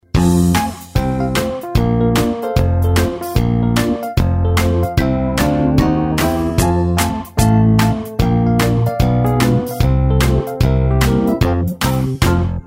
Children's Warm-Up Song for Starting the Day